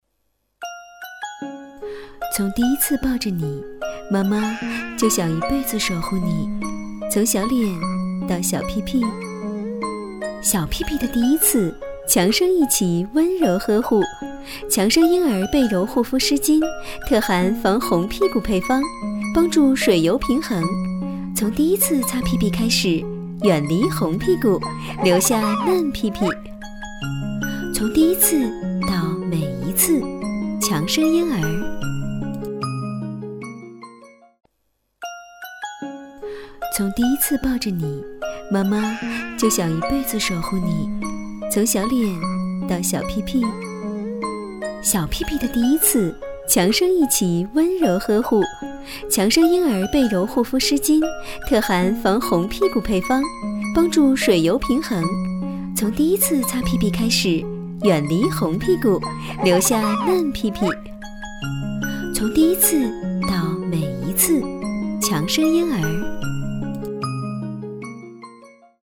国语青年大气浑厚磁性 、沉稳 、娓娓道来 、积极向上 、亲切甜美 、女专题片 、宣传片 、旅游导览 、60元/分钟女S129 国语 女声 专题片-【遗憾深沉】介绍人物怀念家人 人物专题 大气浑厚磁性|沉稳|娓娓道来|积极向上|亲切甜美 - 样音试听_配音价格_找配音 - voice666配音网